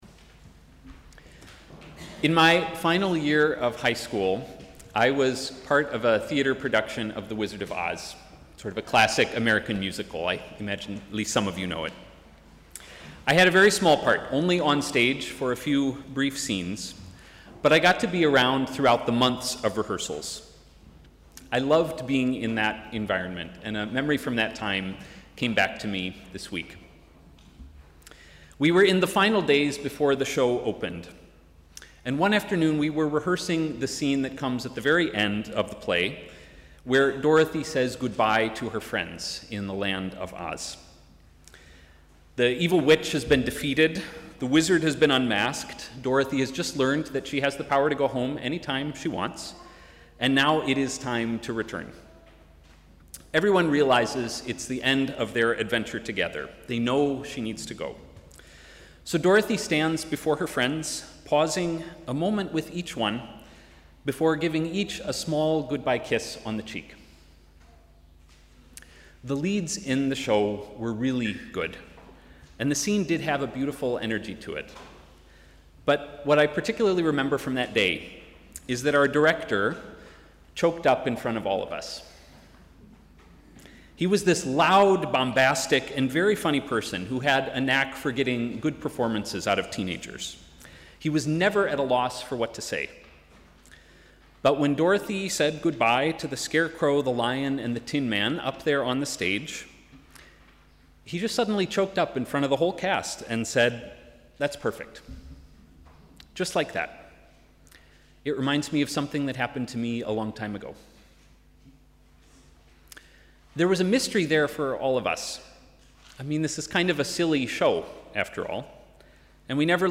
Sermon: ‘Here to be found’